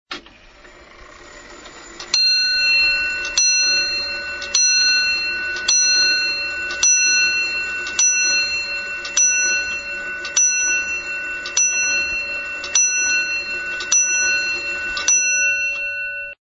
mechanical-chime.mp3